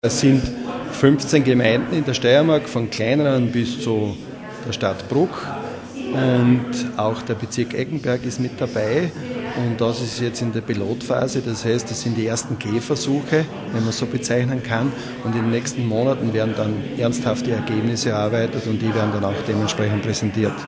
O-Ton: Partnerschaften mit dem Integrationsressort
Erwin Dirnberger, Gemeindebund